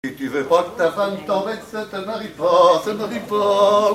Genre brève
Enquête Arexcpo en Vendée-C.C. Saint-Fulgent
Pièce musicale inédite